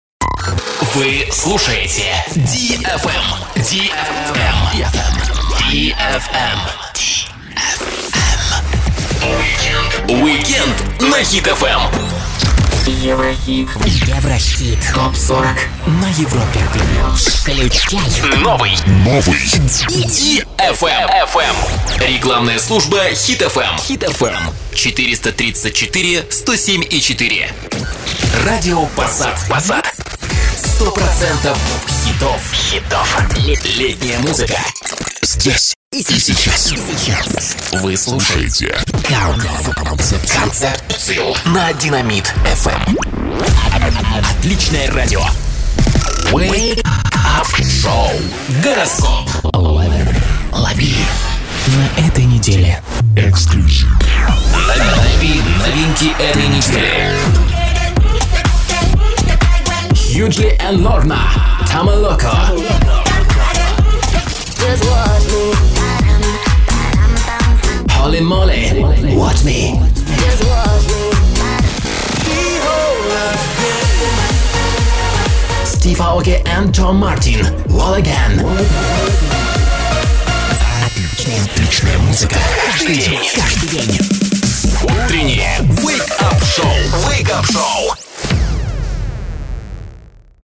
Тракт: микрофон Neumann TLM 103 • предусилитель Long Voice Master • интерфейс RME Babyface Pro • акустическая тон-кабина IzoCab